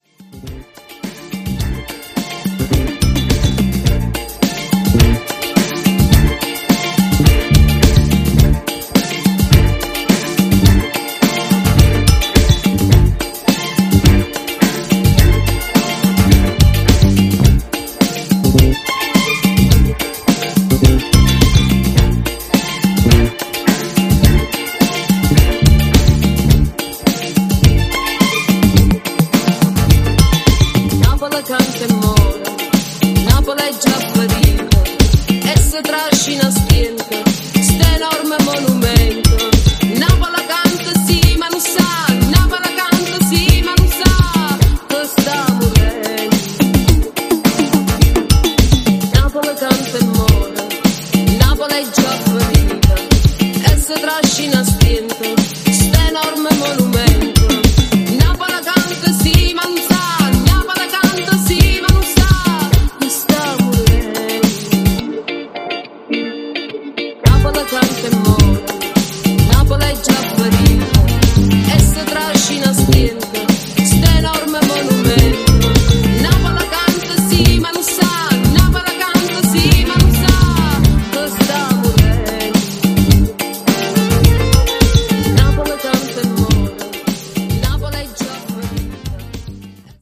イタロ/アフロ/コズミック/バレアリック/ニューウェイヴ等の要素を持った